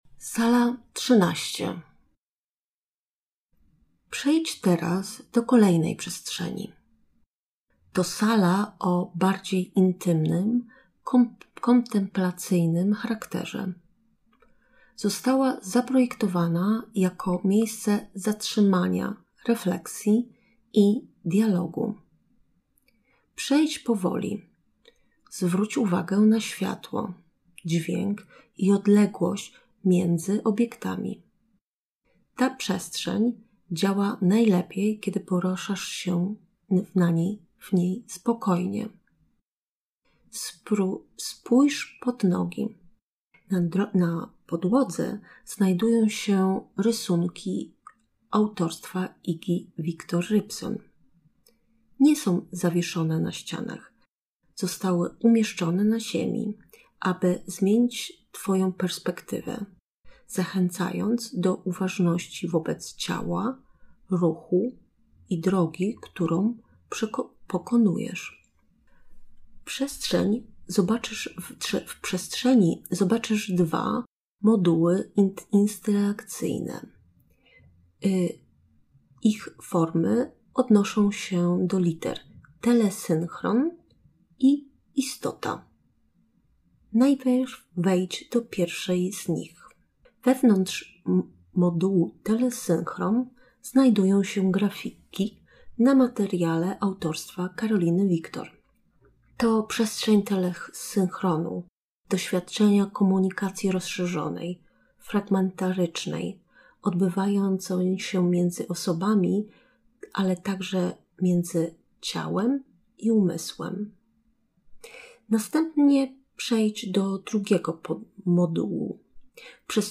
04 Audioprzewodnik po wystawie
audiodeskrypcja